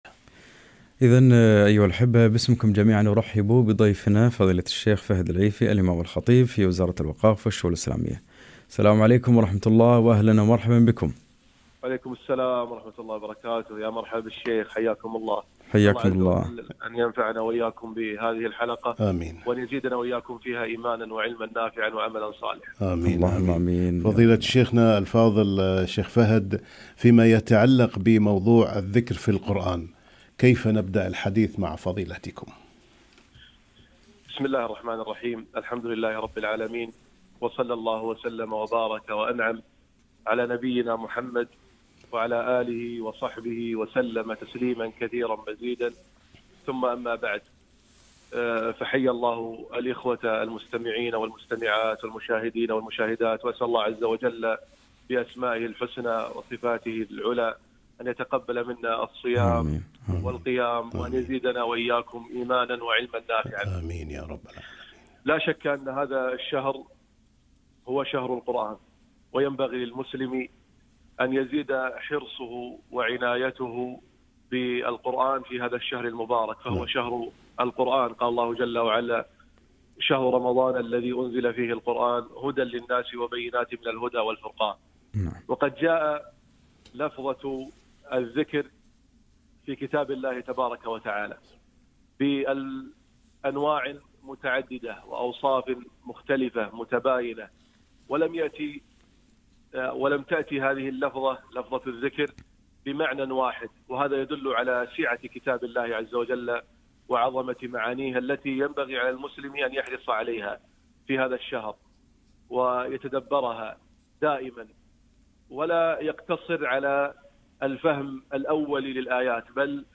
الذكر في القرآن - لقاء إذاعي